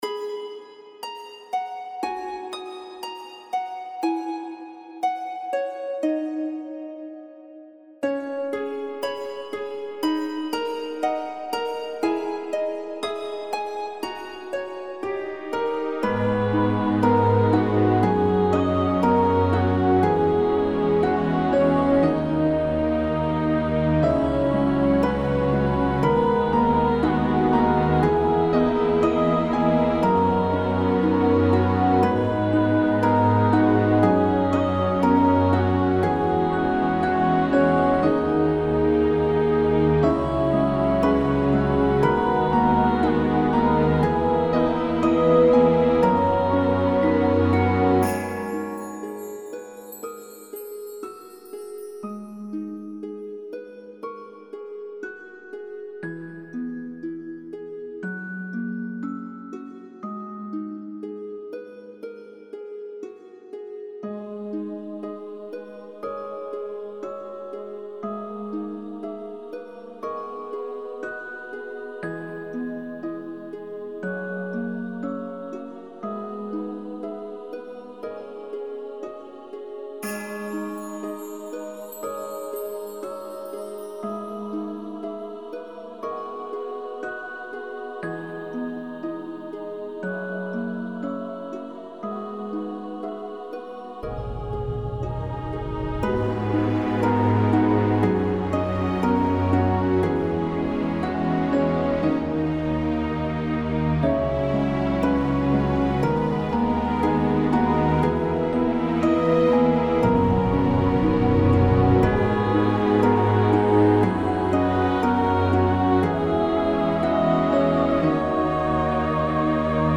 Attention si vous �tes au travail, effet berceuse! 02/09/2008, 14h34
Flying over Eternity - Aion Fan OST.mp3